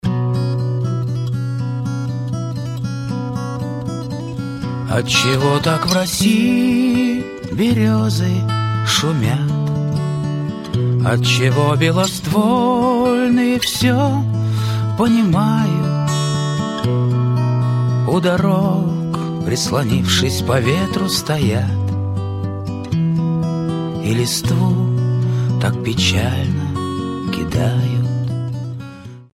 • Качество: 320, Stereo
гитара
грустные
спокойные
ballads
русский рок
лиричные